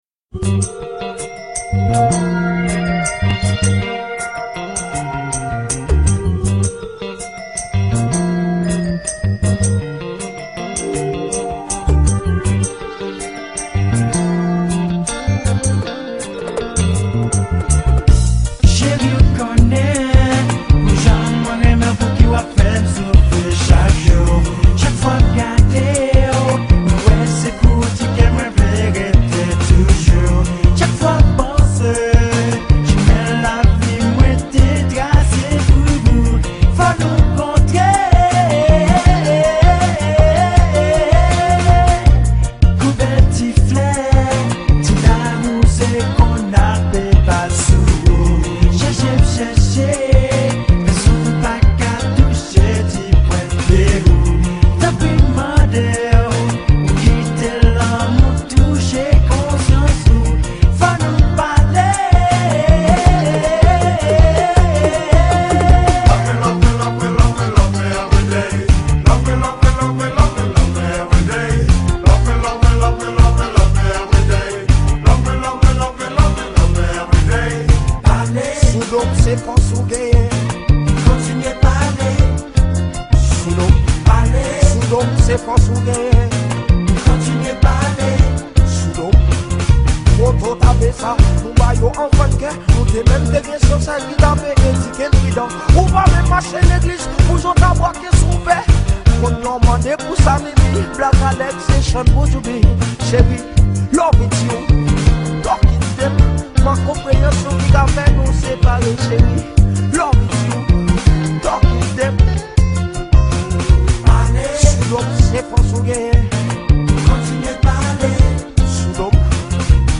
Genre: Ragga Muffin